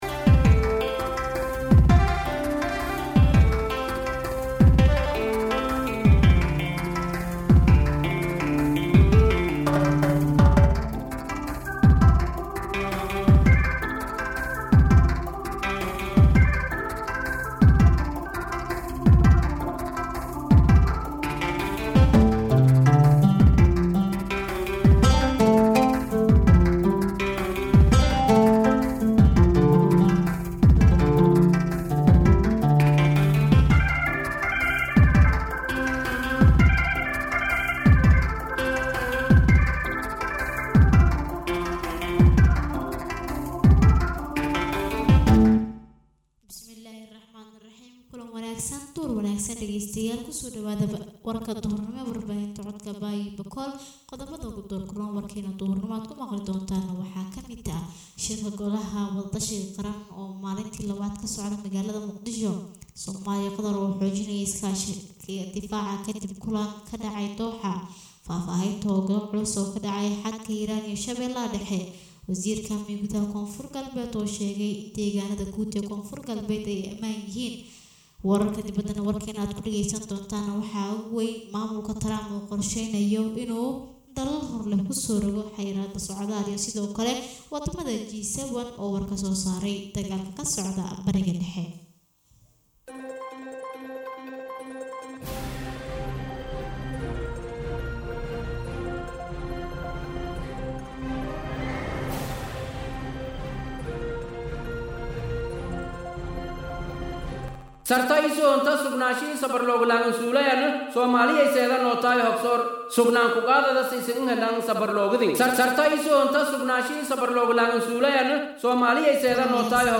{DHAGEYSO} Warka Duhurnimo ee Warbaahinta Radio Codka Baay Iyo Bakool {17.6.2025}